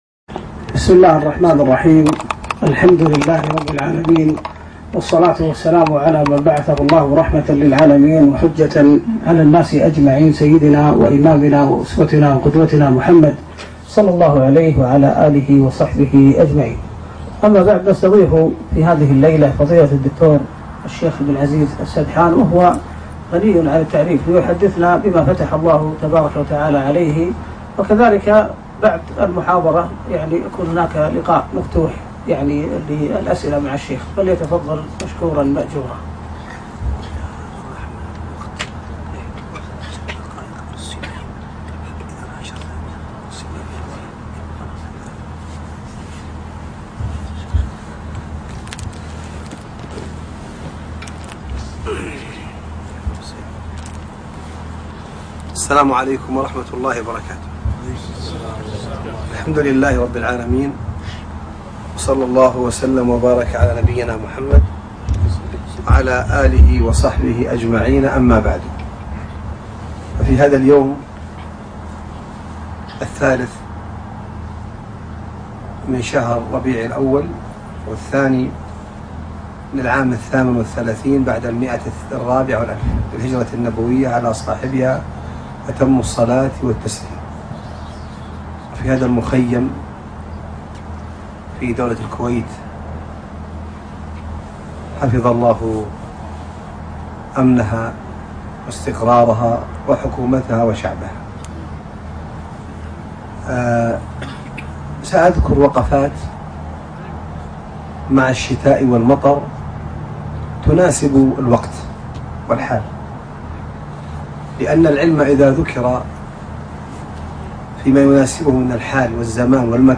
لقاء وكلمة في ديوان شباب الفحيحيل